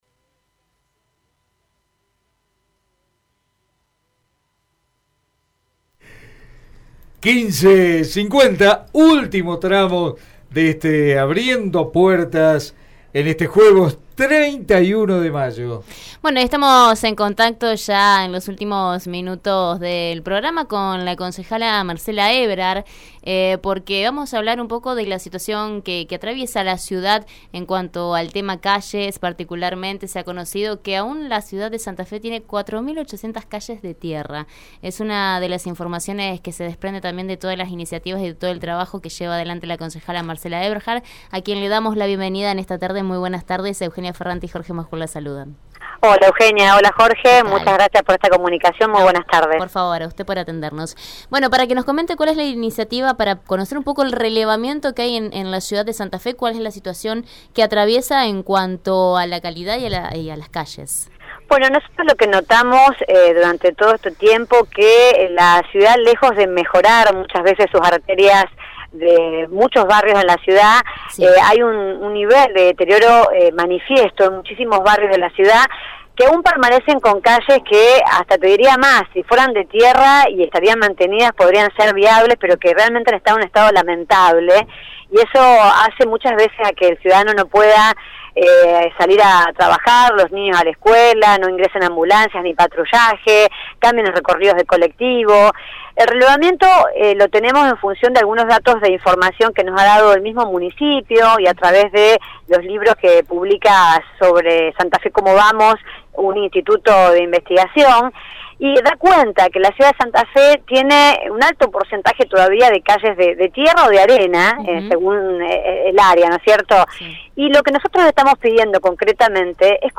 La concejala Marcela Aeberhard habló en Radio EME sobre el estado de las calles de la ciudad de Santa Fe.